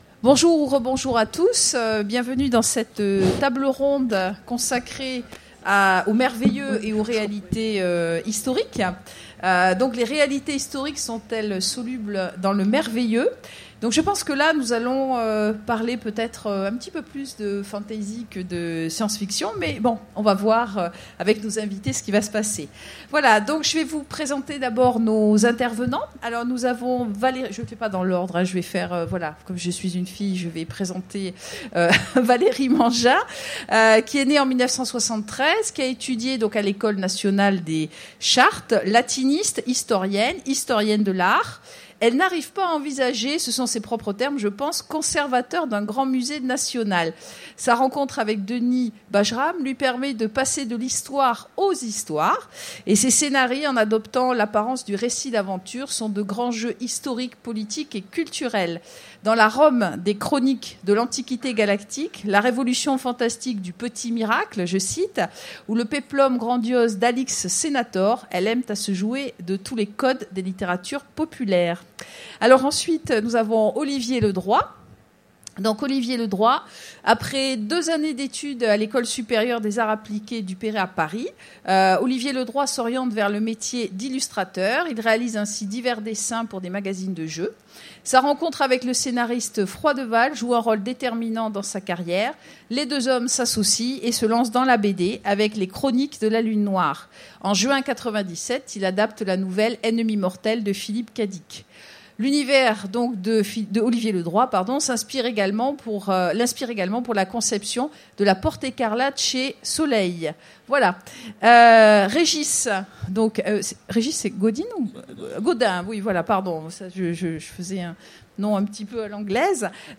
Utopiales 2015 : Conférence Les réalités historiques sont-elles solubles dans le merveilleux?